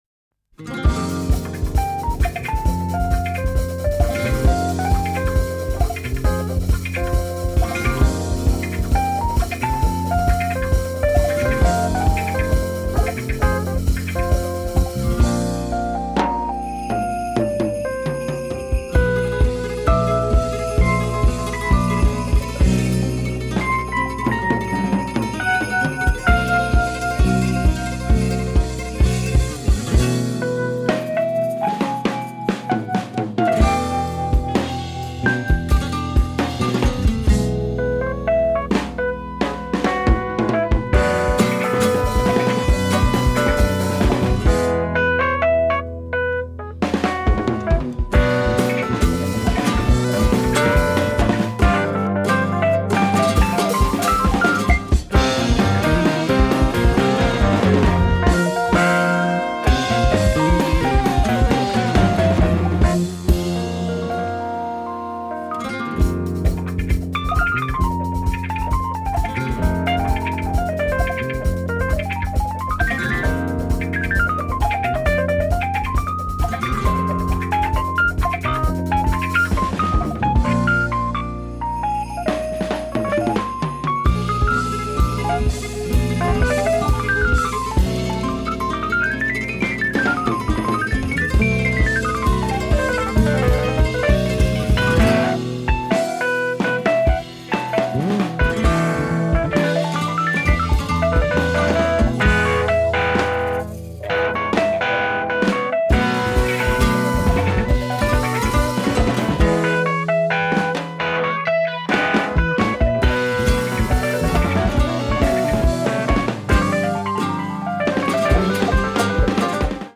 ダイナミックなジャズ・ロックにスペイン音楽が息衝く傑作！